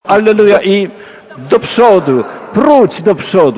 , który został spróbkowany z częstotliwością 22050 Hz oraz rozdzielczością 16 bitów.